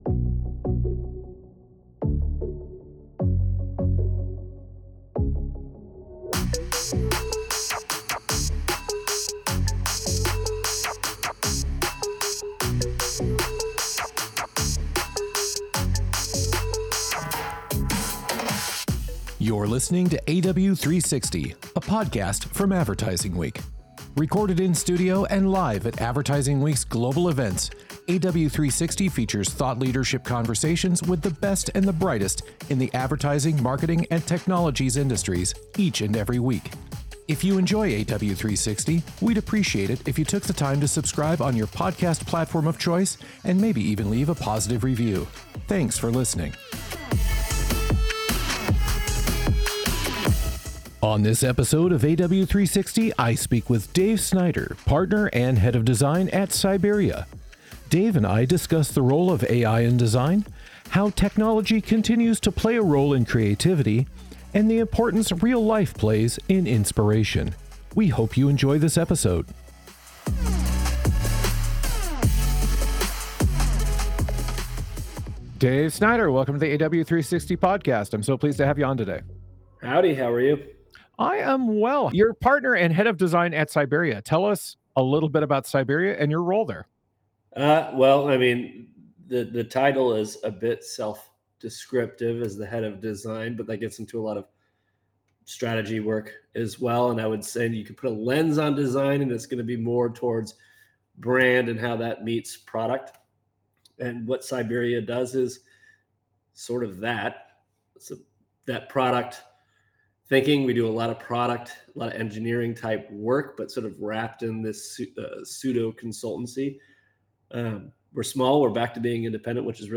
1 Live from AWNewYork: Addressing critical ad ops challenges with a Digital Advertising Operating System 23:29